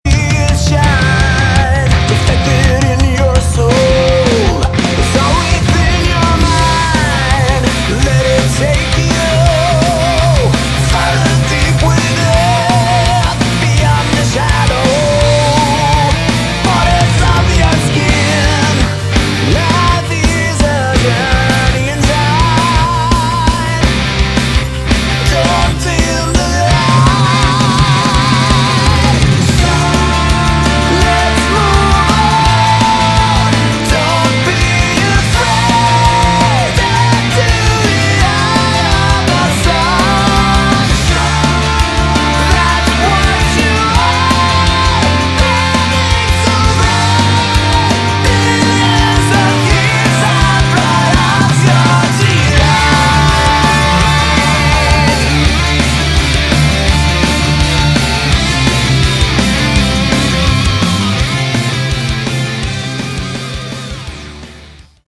Category: Melodic Rock / AOR
guitar, bass, synthesizer, backing vocals
drums, percussion
keyboards